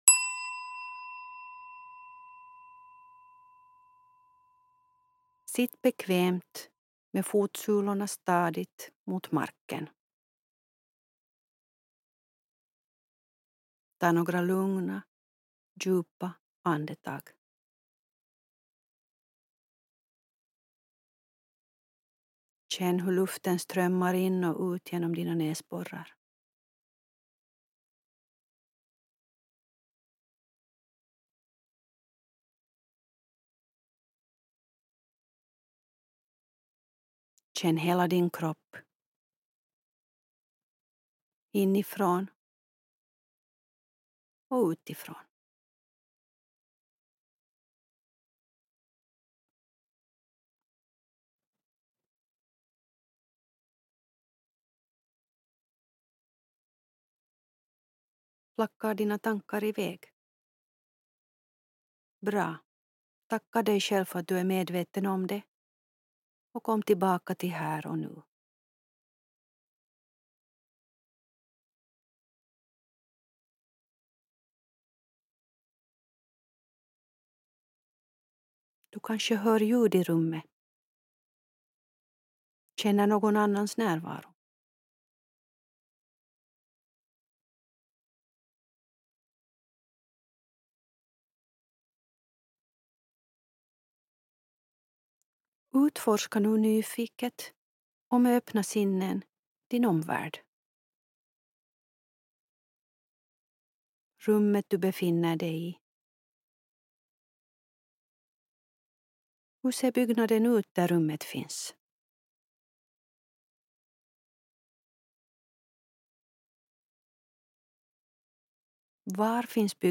Förlossningen brukar framskrida bättre om du andas lugnt, slappnar av och håller dig i rörelse under förlossningen. Hjälp för att göra det får du i följande övning i medveten närvaro.